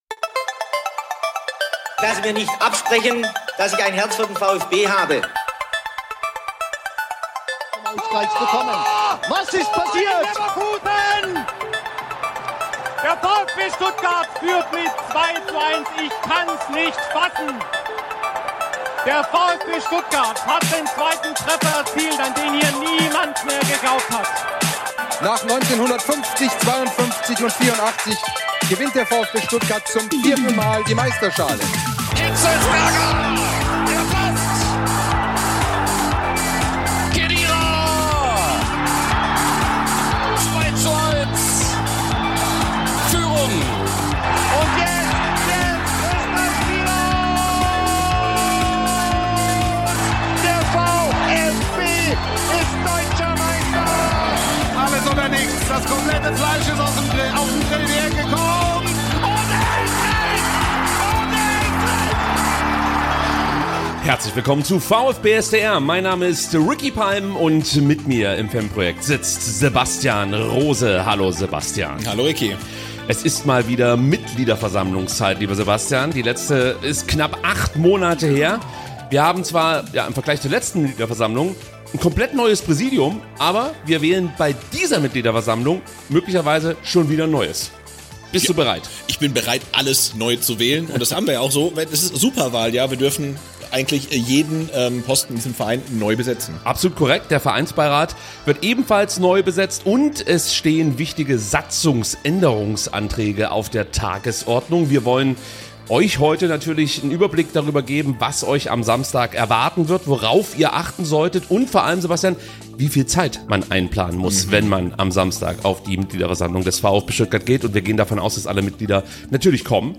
Der Podcast bietet eine Mischung aus Spielanalysen, Vereinsneuigkeiten, Interviews und Diskussionen rund um den Verein.